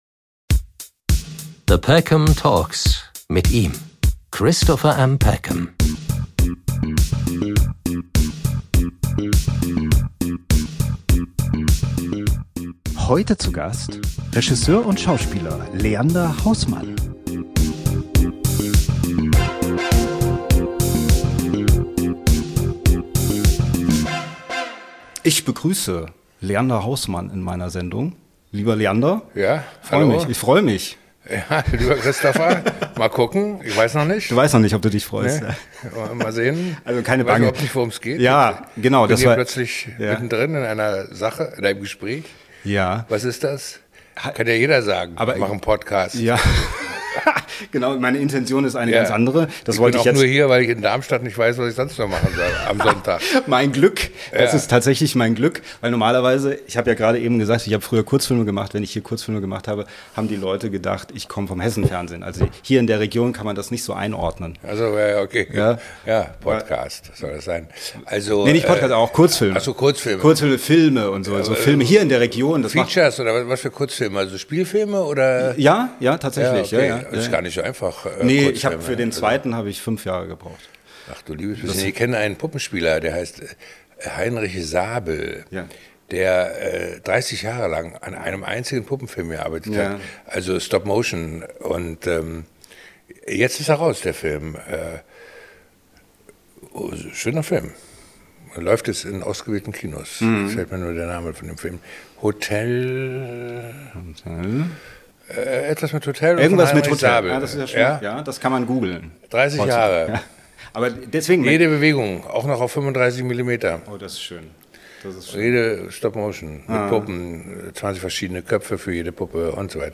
Beschreibung vor 2 Monaten Ich habe Regisseur Leander Haußmann in Darmstadt getroffen und spreche mit ihm über die Zeiten, in denen wir leben - und warum es gerade jetzt genügend Gründe gibt, mit dem Trinken anzufangen. Zwischendrin blickt Leander immer wieder zurück, und unterhält uns mit interessanten, teils komischen, aber stets kritischen Geschichten seines Lebens.